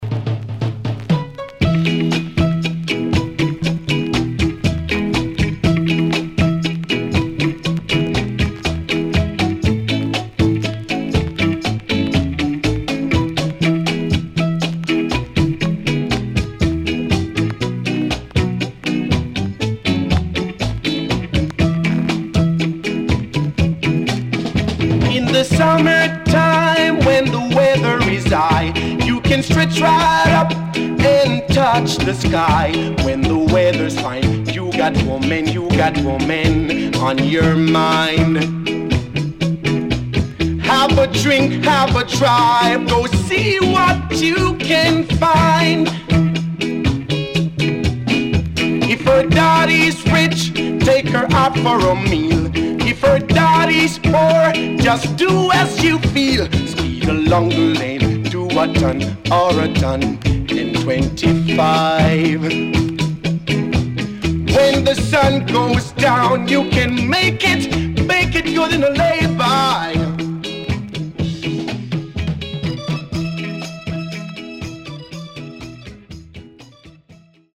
Nice Early Reggae Vocal
SIDE A:所々チリノイズがあり、少しプチノイズ入ります。